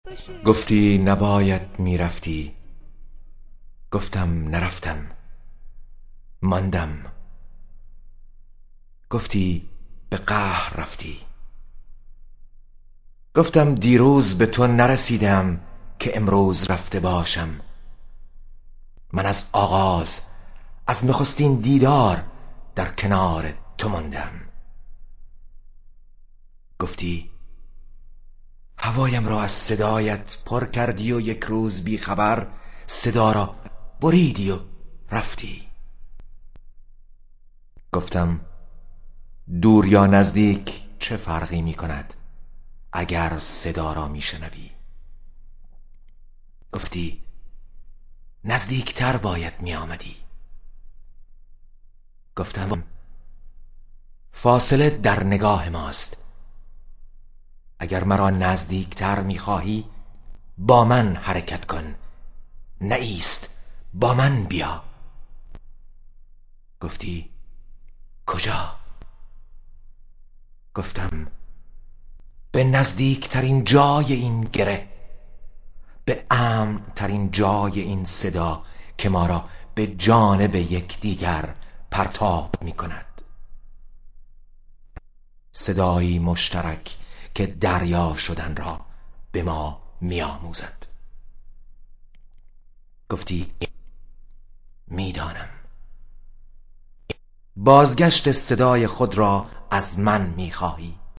دانلود دکلمه گفتی نباید می رفتی با صدای شهیار قنبری
گوینده :   [شهیار قنبری]